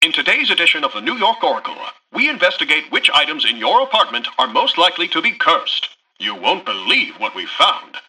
Newscaster_headline_29.mp3